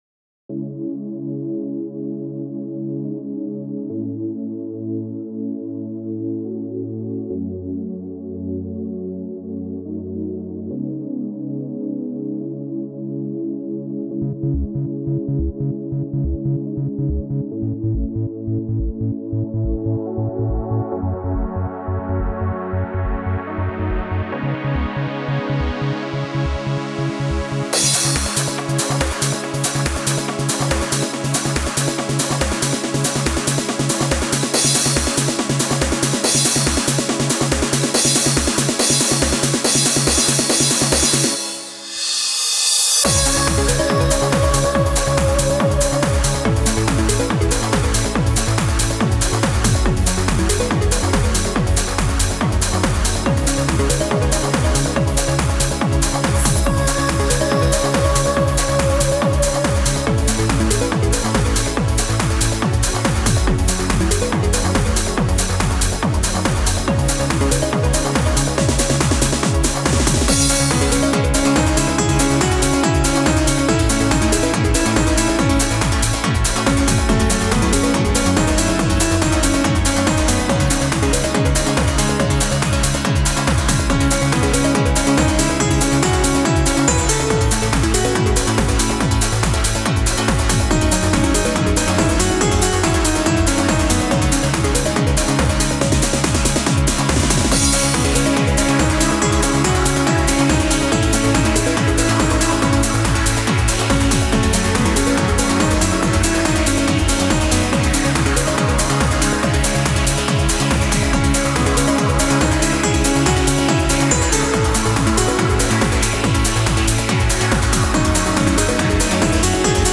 Trance
この曲はTranceというジャンルで作った中ではちょっと特殊なんだよ。